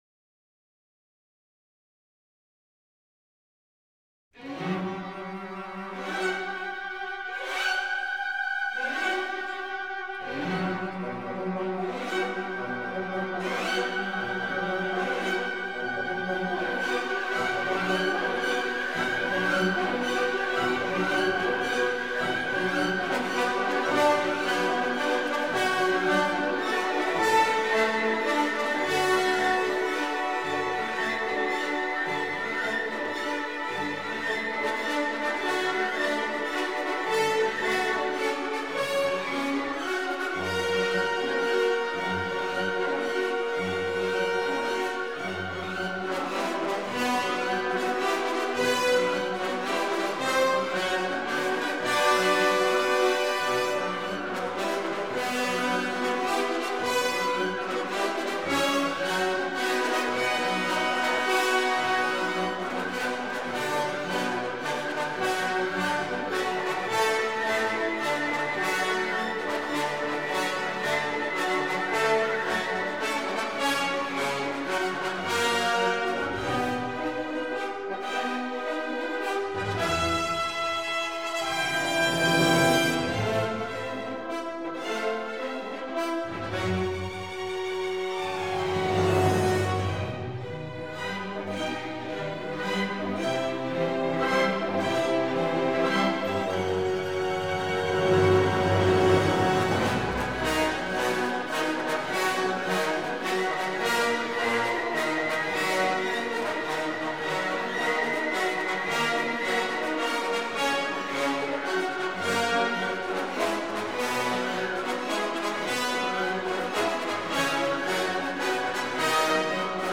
Главная / Песни для детей / Классическая музыка